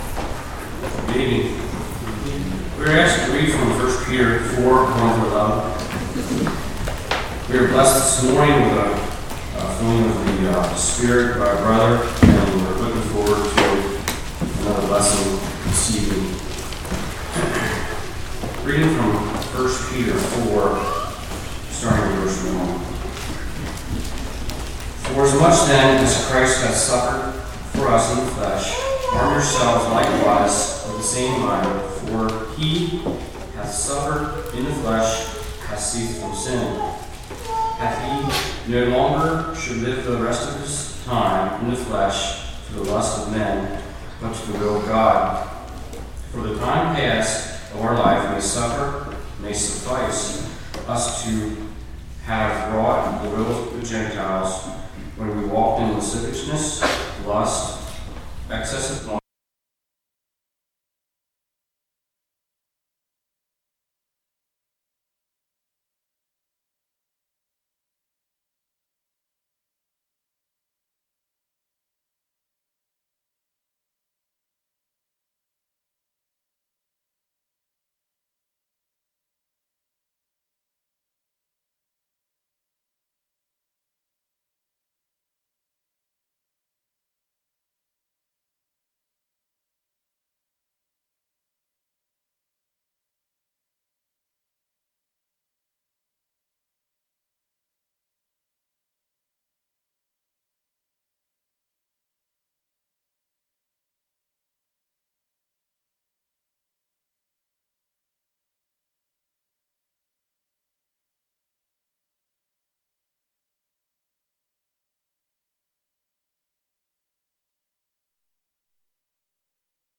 1 Peter 4:1-11 Service Type: Evening Be of Sound Judgment and Sober in Prayer.